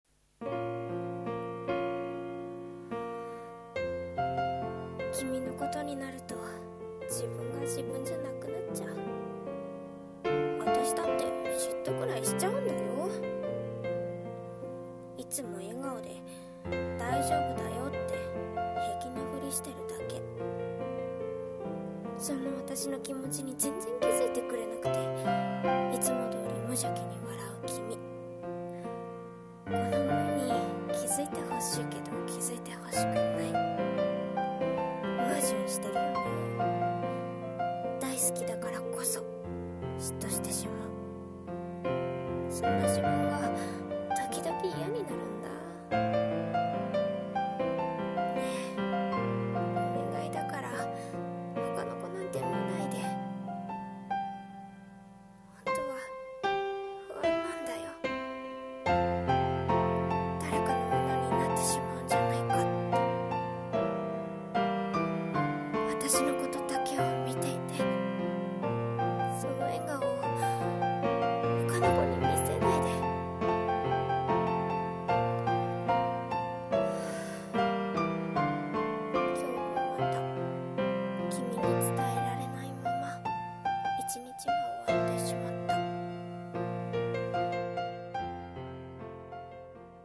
声劇/朗読【嫉妬 自分だけに笑顔を見せて…】